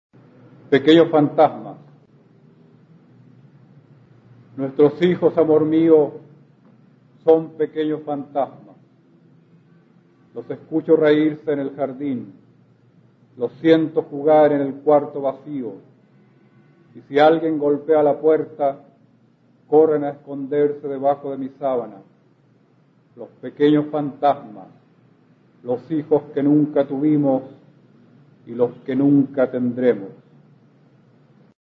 Escucharás al poeta chileno Óscar Hahn, perteneciente a la Generación del 60, recitando su poema Pequeños fantasmas, del libro "Mal de amor" (1981).
Poema